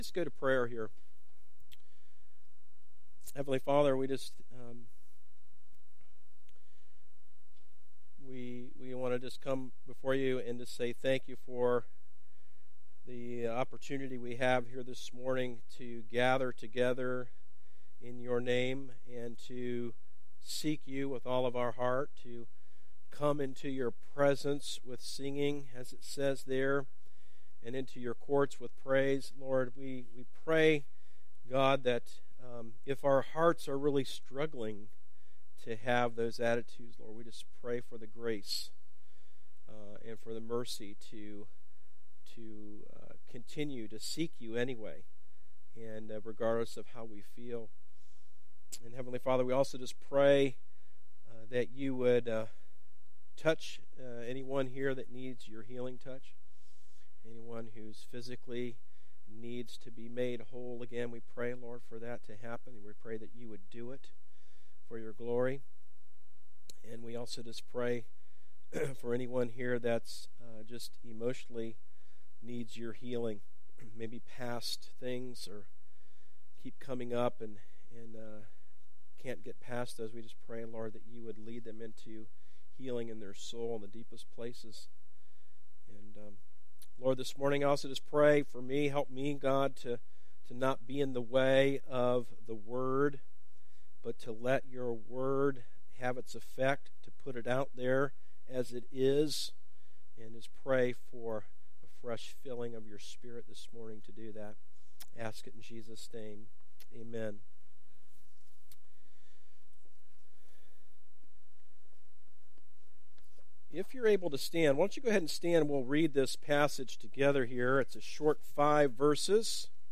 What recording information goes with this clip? Recent Sermon - Darby Creek Church - Galloway, OH